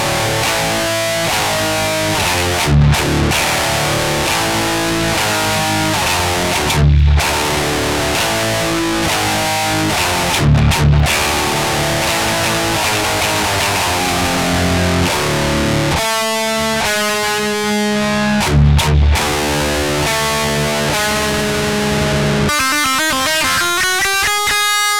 Правый канал - оригинал, левый, соответсвенно, профайл.
В том виде, в котором есть самое "недоученное" - это низ, он заметно меньше чем в оригинале.